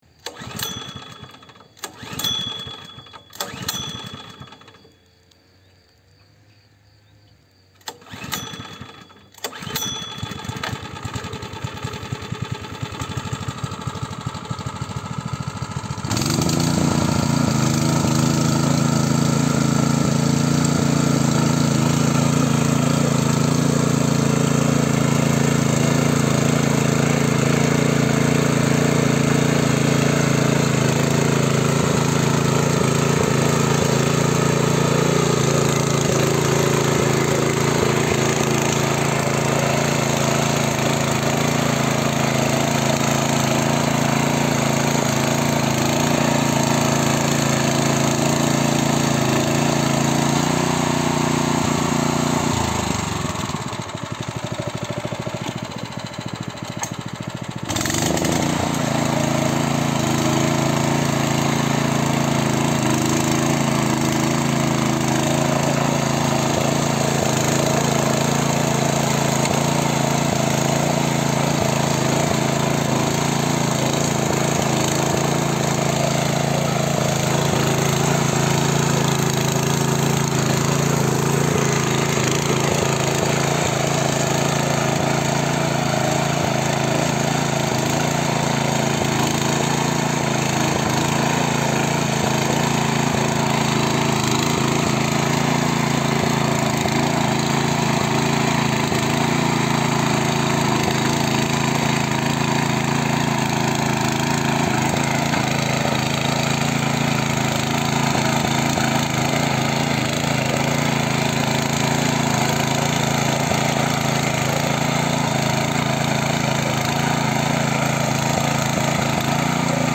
Мотоблок Хонда пытаются завести шворкой, заводится и начинает работать